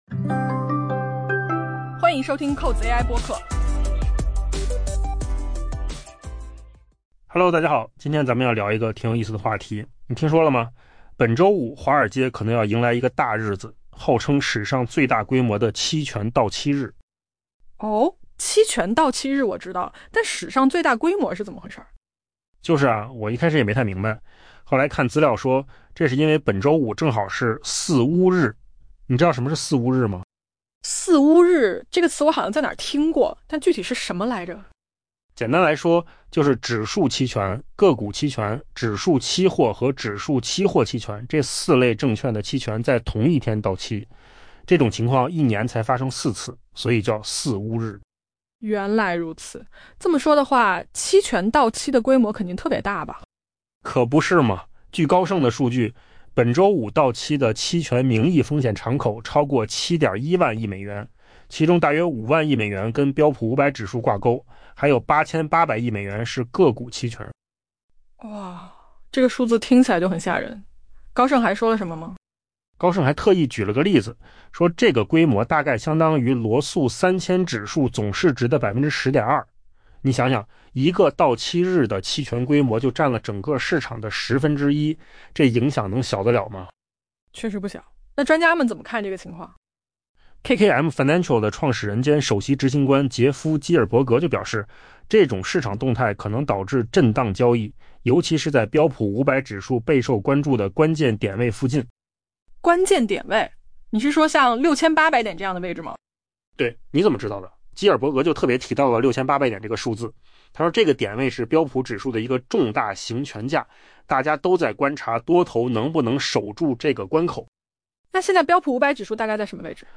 AI 播客：换个方式听新闻 下载 mp3 音频由扣子空间生成 本周五，华尔街可能迎来一个波动剧烈的交易日。